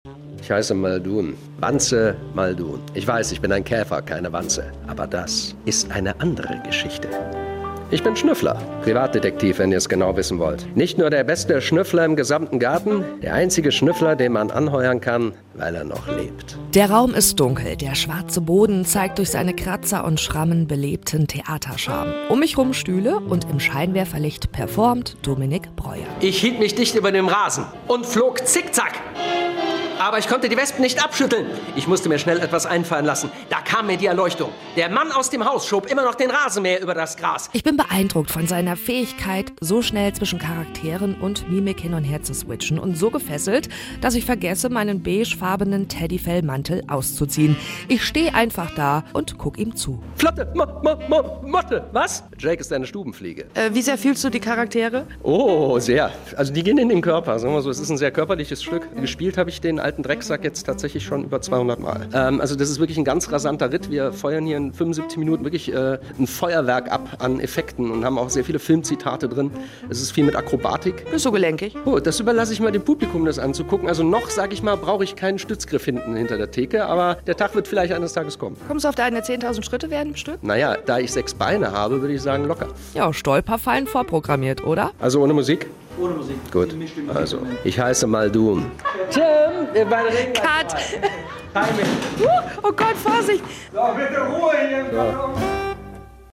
bei den Proben besucht